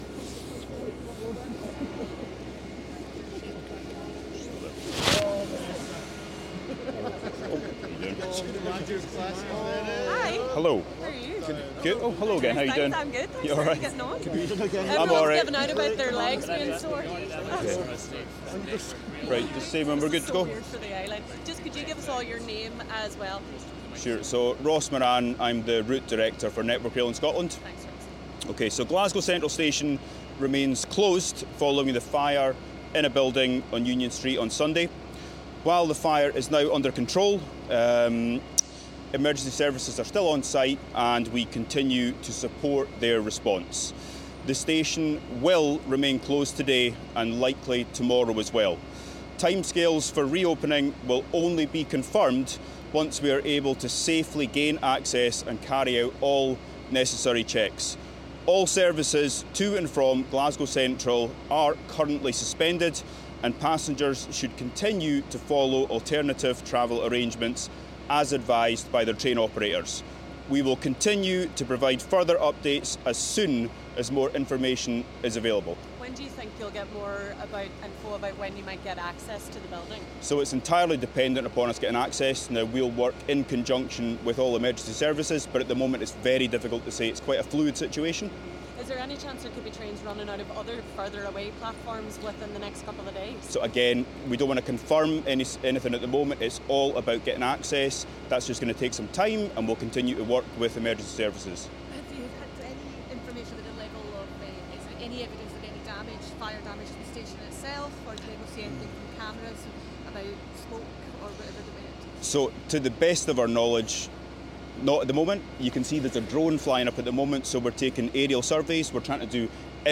Press conference raw audio